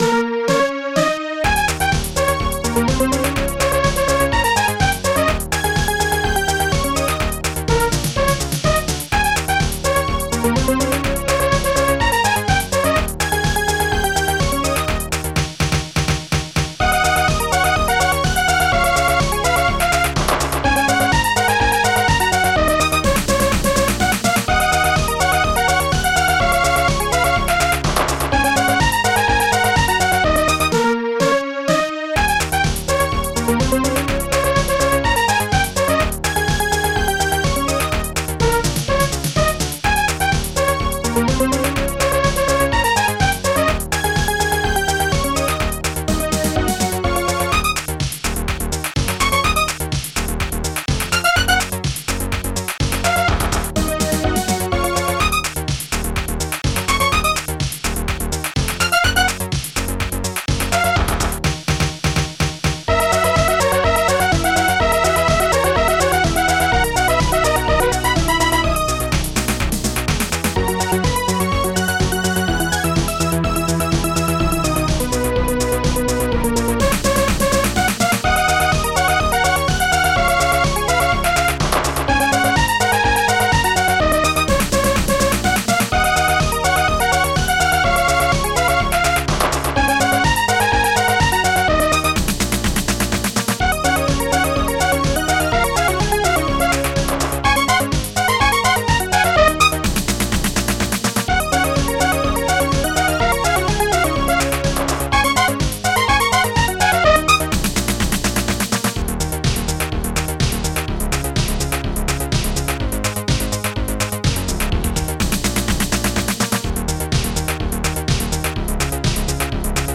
ST-02:licks st-01:hihat2 ST-01:popsnare2 ST-01:strings1 ST-01:bassdrum2 ST-02:Tunebass ST-01:shaker ST-01:claps1